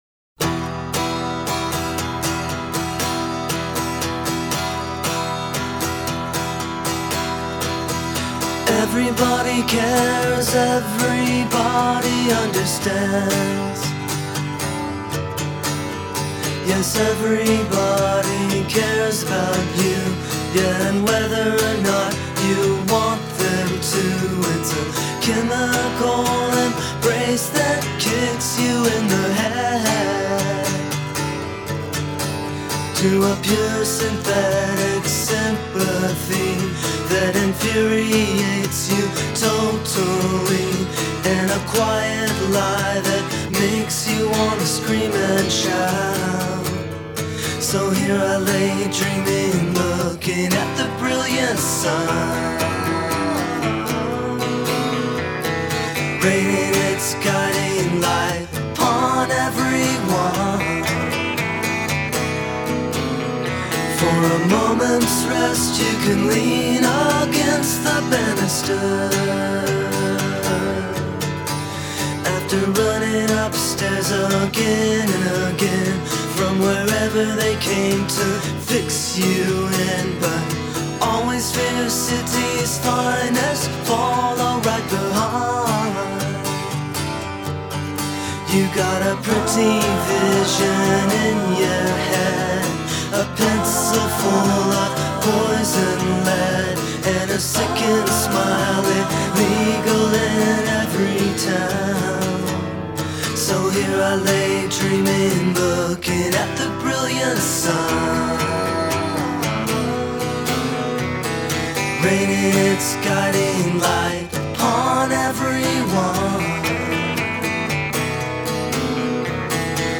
a song that sounds as if it’s going nowhere until